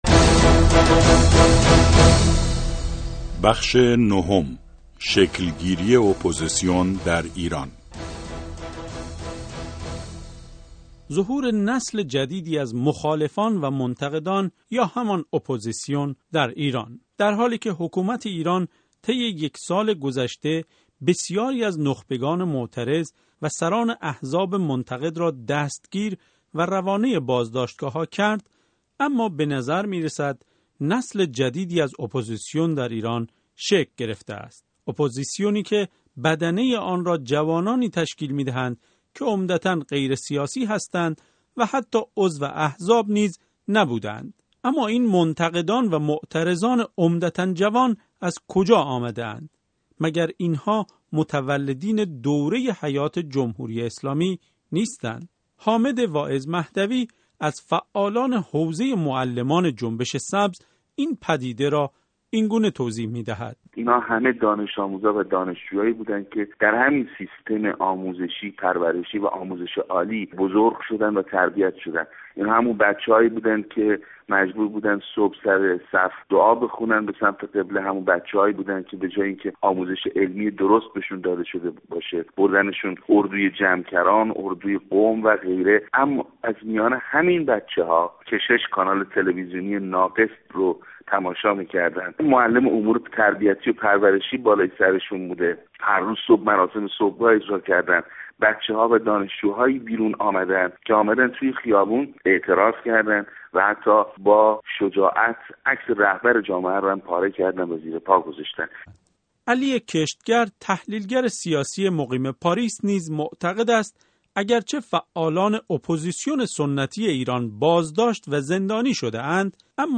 گزارش رادیویی از خرداد تا خرداد (۹): ظهور نسل جدید اپوزيسيون در ايران